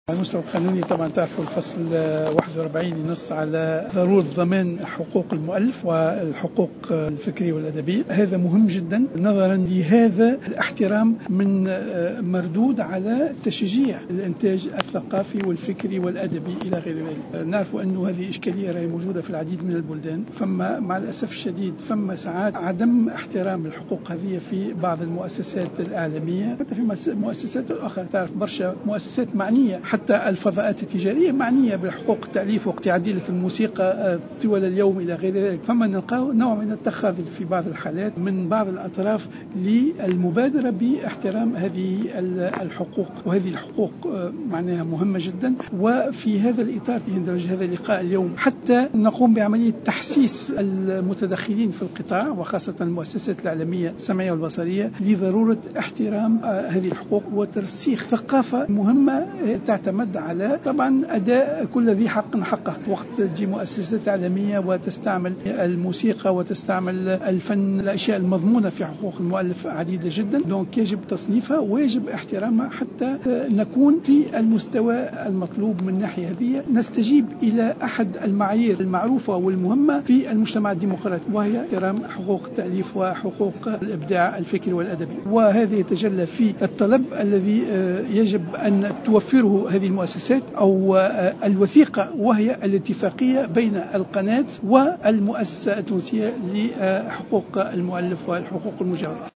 أكد رئيس هيئة الإتصال السمعي البصري النور اللجمي في تصريح اعلامي على هامش ورشة عمل نظمتها الهيئة مع المؤسسة التونسية لحقوق المؤلف اليوم الثلاثاء ضرورة ضمان حقوق المؤلف والحقوق الأدبية والفكرية وذلك لتشجيع الانتاج الثقافي والفكري والأدبي .